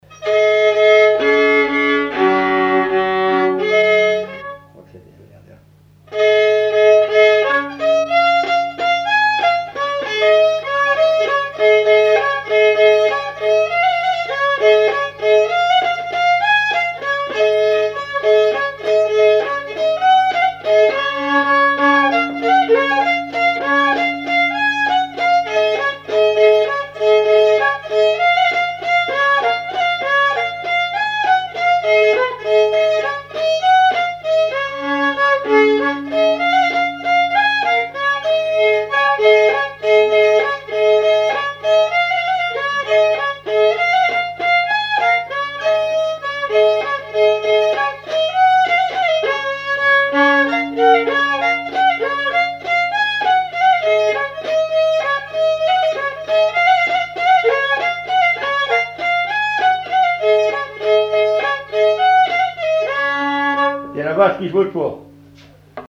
danse : valse
répertoire d'air pour la danse au violon et à l'accordéon
Pièce musicale inédite